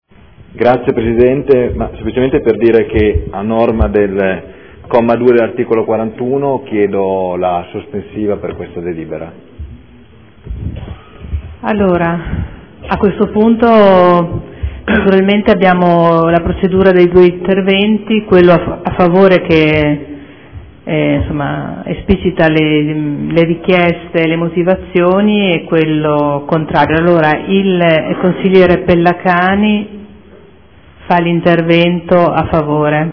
Seduta del 23/01/2014 Adesione del Comune di Bologna a Emilia Romagna Teatro Fondazione in qualità di socio fondatore necessario. Chiede la sospensiva.